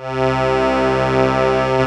C2 ACCORDI-L.wav